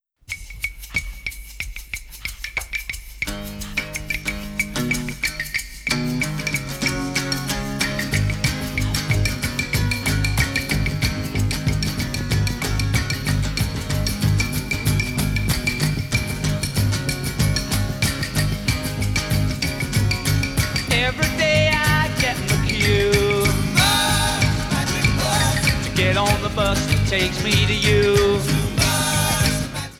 1985 USA CD   1988 USA CD   2007 Japan CD